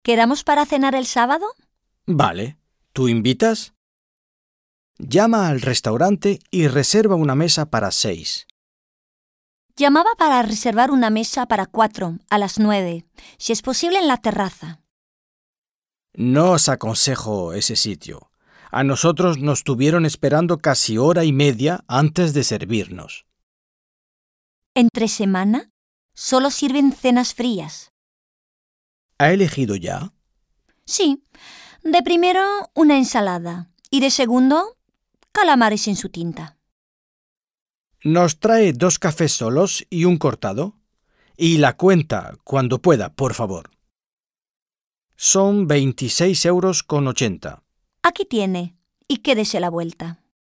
Un peu de conversation - Au restaurant